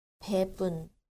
• 부분
• bubun